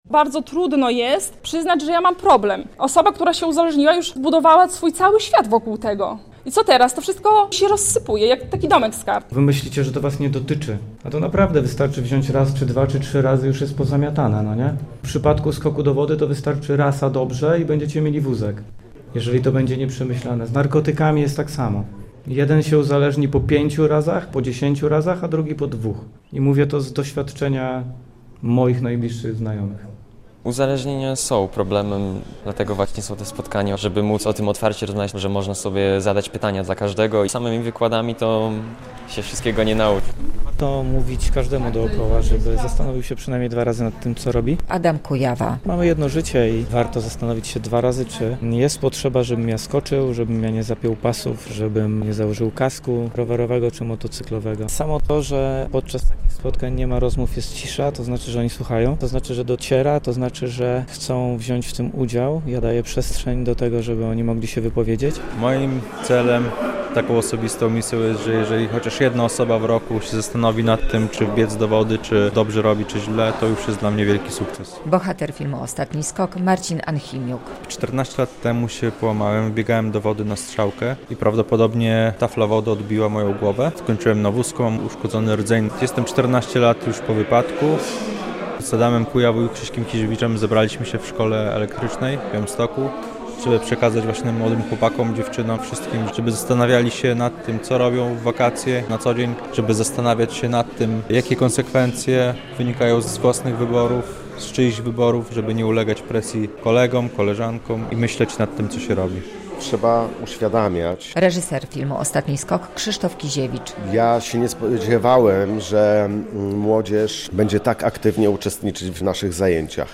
By zbliżający się letni wypoczynek był bezpieczny, w Zespole Szkół Elektrycznych w Białymstoku zorganizowano specjalne spotkanie pod hasłem "Strefa świadomych wakacji - cudze błędy? Twoja lekcja na przyszłość!"
Goście mówili o swoich doświadczeniach, odpowiadali na pytania uczniów.